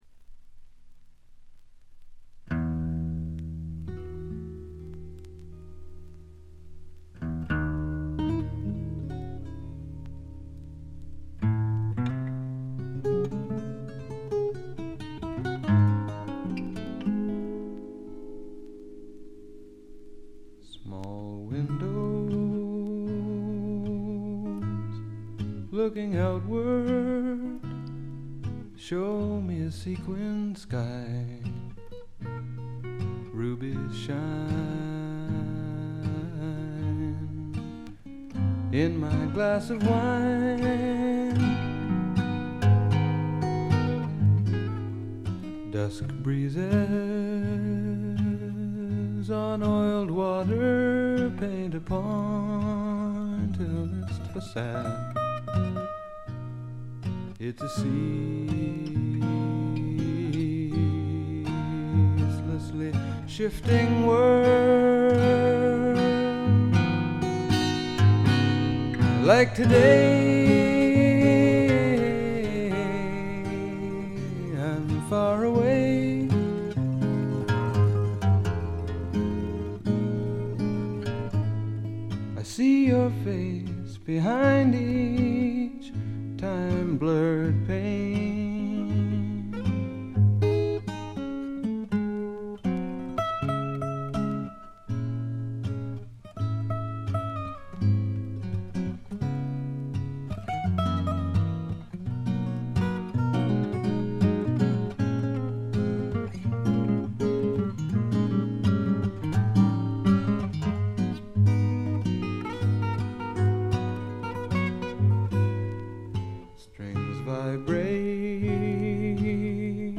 ところどころでチリプチ。
静謐で内省的なホンモノの歌が聴ける名作です。
ほとんどギターの弾き語りで、ギターインストのアコギの腕前も素晴らしいです。
試聴曲は現品からの取り込み音源です。